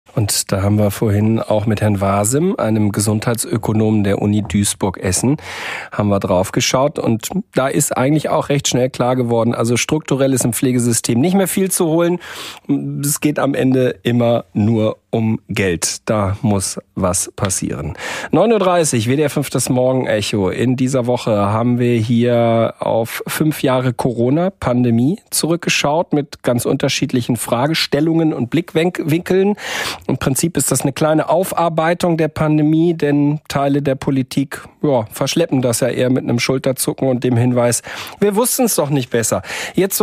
Haben sich seitdem die Arbeitsbedingungen von Pflegenden verbessert? Ein Interview